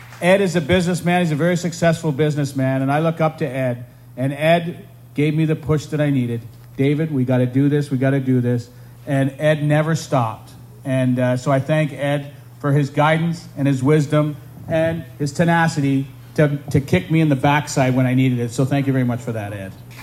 The second annual YMCA of Central East Ontario (YMCA of CEO) Night in White Garden Party was held at Connon Nurseries on Friday evening.